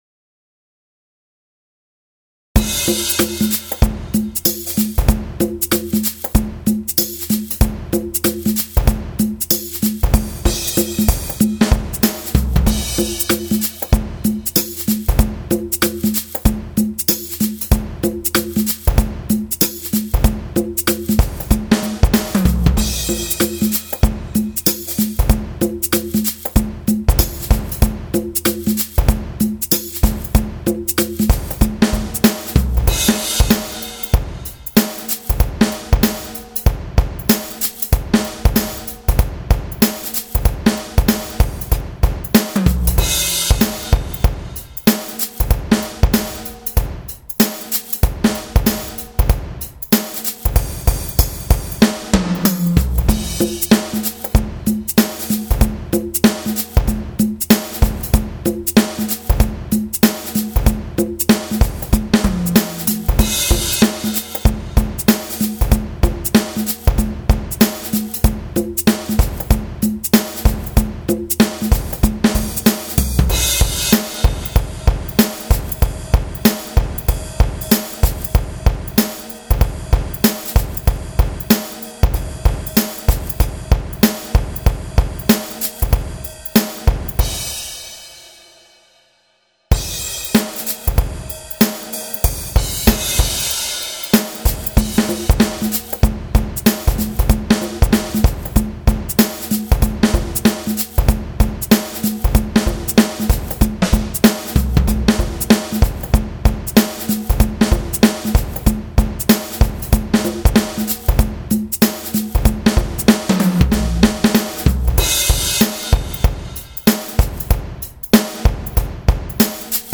Drum Grooves
02 Hip Hop 95.mp3